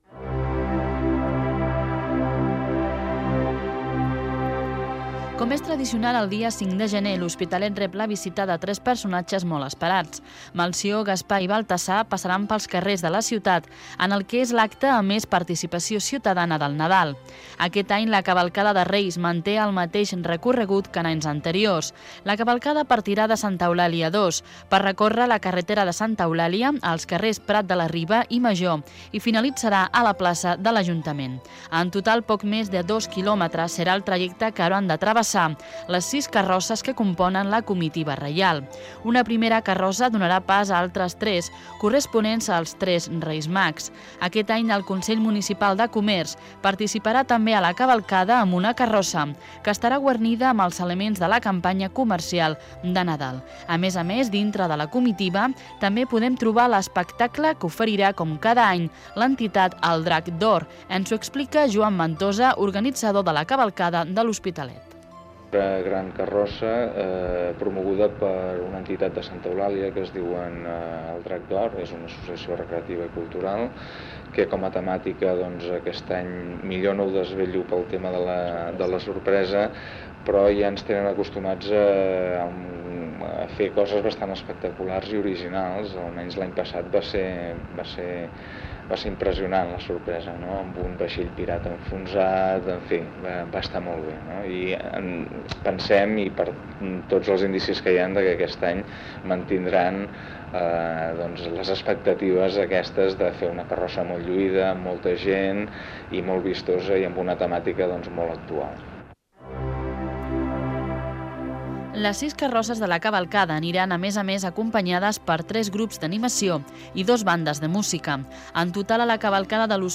Crònica de la cavalcada dels Reis Mags des de Ràdio L'Hospitalet